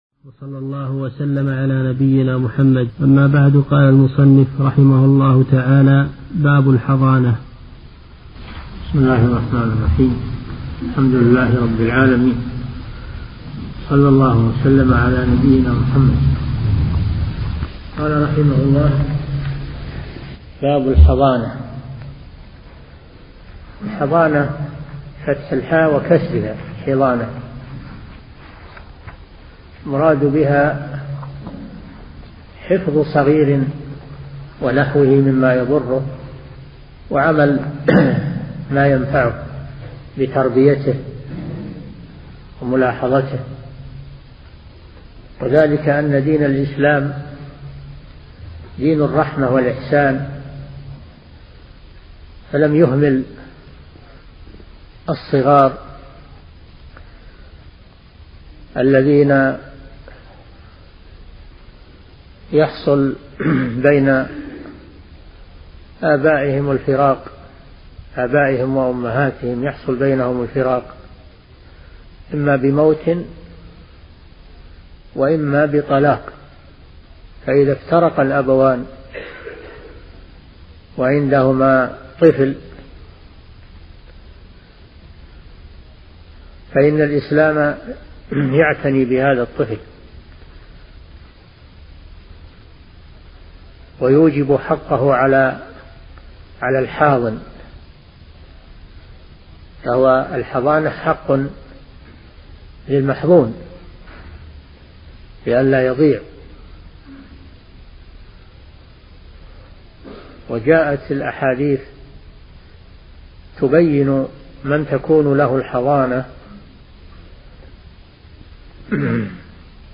أرشيف الإسلام - ~ أرشيف صوتي لدروس وخطب ومحاضرات الشيخ صالح بن فوزان الفوزان